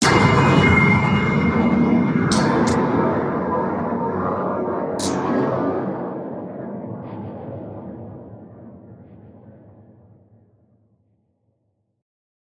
Звук плохих воспоминаний в голове человека